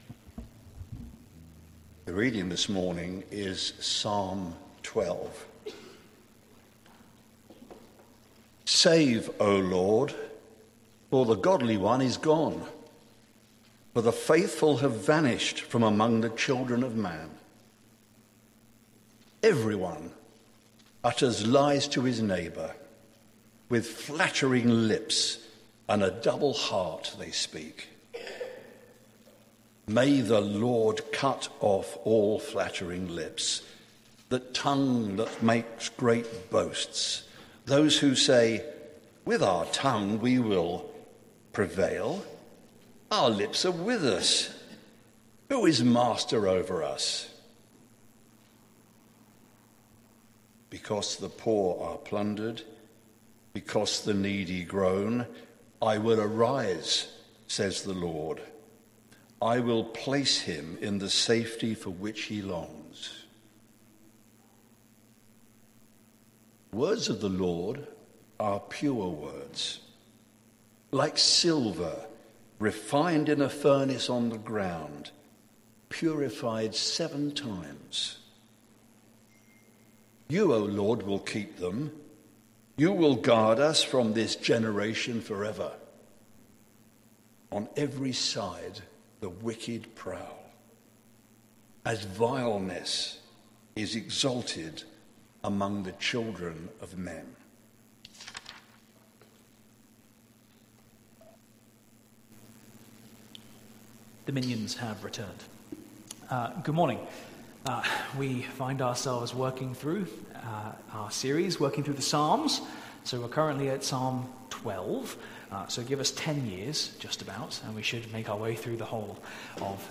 Sermon Series: The Psalms